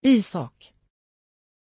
Aussprache von Isaak
pronunciation-isaak-sv.mp3